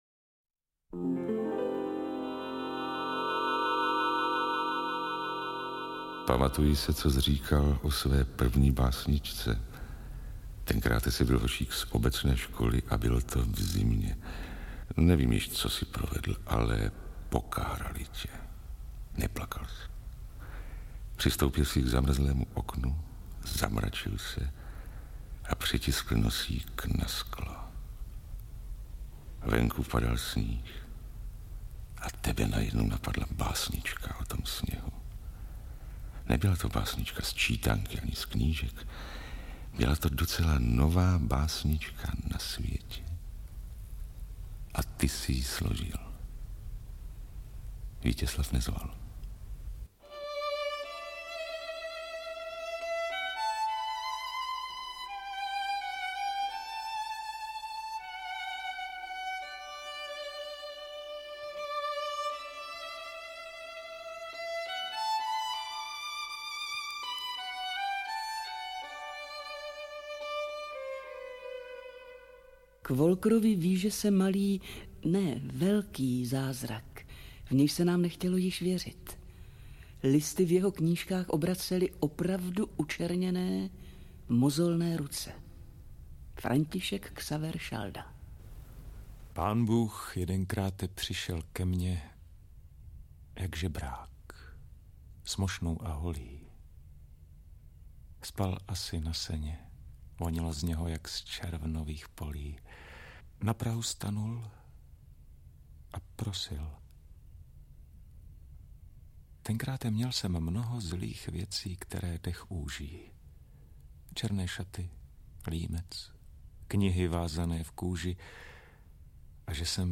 • AudioKniha ke stažení Zahrej, chraplavý Aristone. Kompozice - portrét na téma Wolker